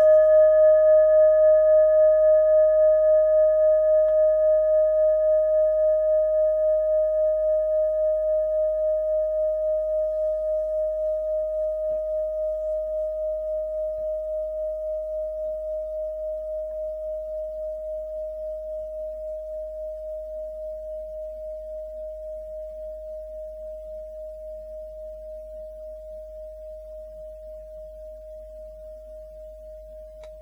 Kleine Klangschale Nr.2
Sie ist neu und ist gezielt nach altem 7-Metalle-Rezept in Handarbeit gezogen und gehämmert worden.
Der Neptunton liegt bei 211,44 Hz, das ist nahe beim "Gis".
kleine-klangschale-2.wav